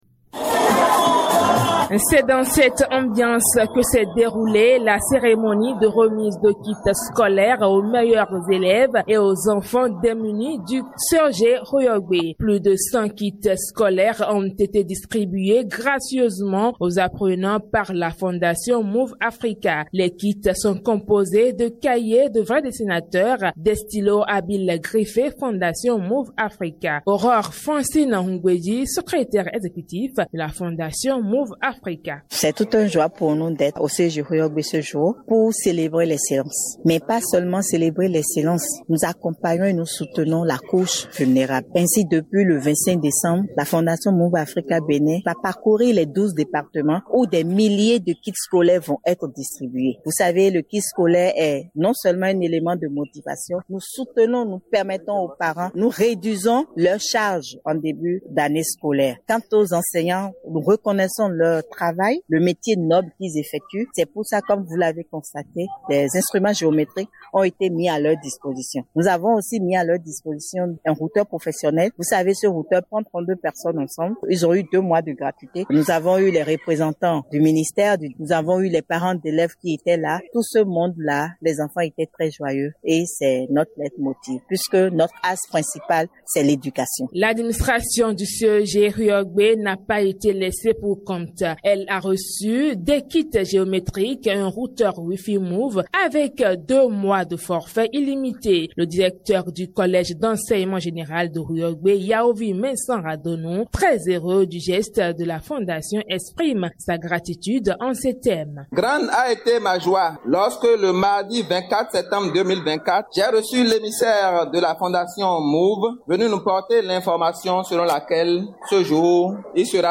La cérémonie de remise des kits et matériels s’est déroulée en présence des élèves, du personnel enseignant, des parents d’élèves et des autorités politico-administratives.